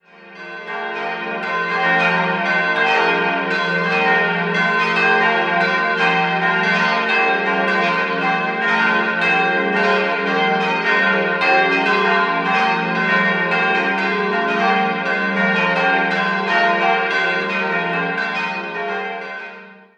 Franz-Xaver-Glocke f'+0 1.025 kg 1.160 mm 1971 ??? Marienglocke g'-2 537 kg 1.010 mm 1950 ??? Gefallenenglocke b'-2 283 kg 840 mm 1950 ??? Evangelistenglocke c''+0 ??? kg 940 mm um 1300 unbekannt Quelle: Pfarramt Möning